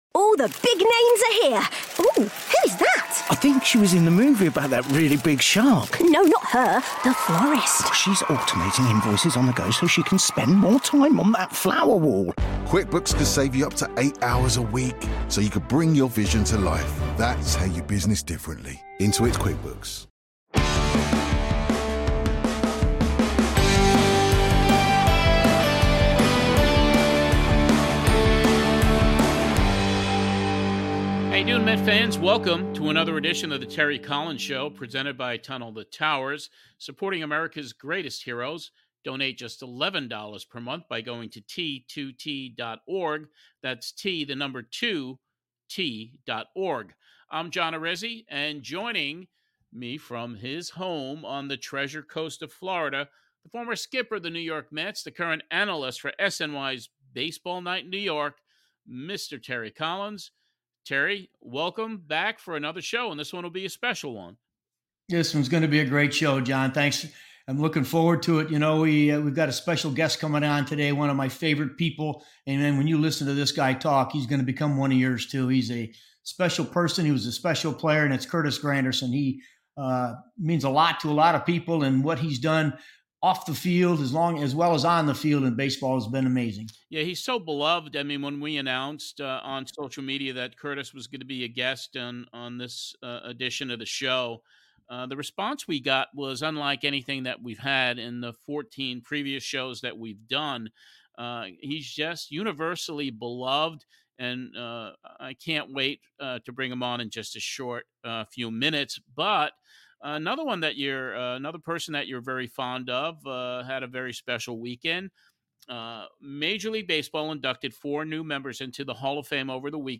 Grandy reminisces with Terry about the 2015 Mets, his career and philanthropic work, and answers questions from our listeners.
Host: Terry Collins